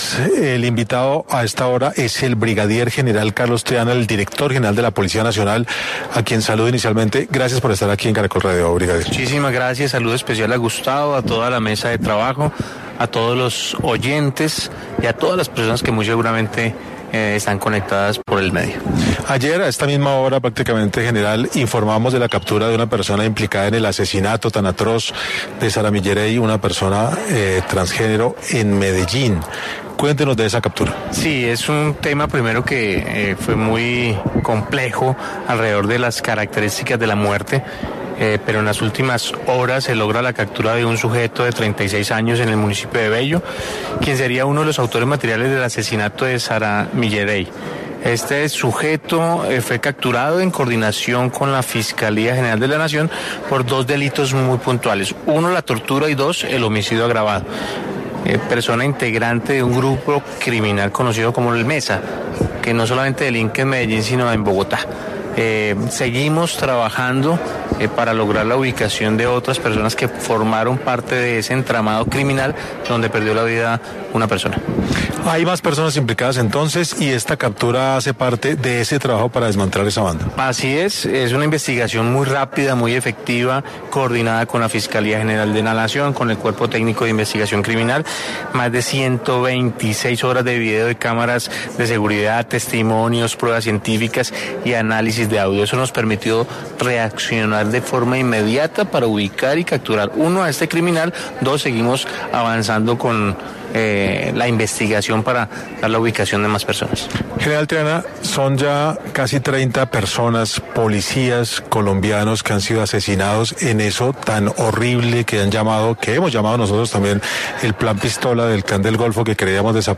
Carlos Triana, brigadier general de la Policía Nacional, estuvo en 6AM para abordar varios temas de seguridad que afectan al país.
En este orden de ideas, el brigadier general de la Policía Nacional, Carlos Fernando Triana Beltrán, pasó por los micrófonos de 6AM para abordar varias situaciones de interés nacional, que aquejan la seguridad del país.